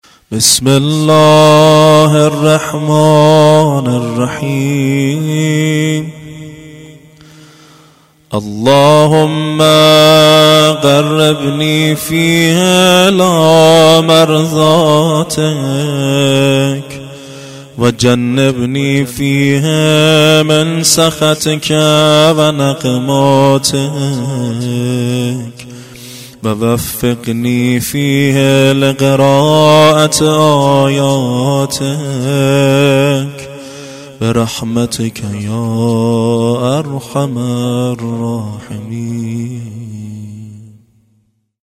دعای ایام ماه مبارک رمضان